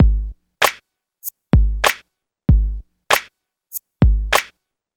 Original creative-commons licensed sounds for DJ's and music producers, recorded with high quality studio microphones.
Old school gangsta rap drumloop with a tight kick tuned in G# and a snappy clap.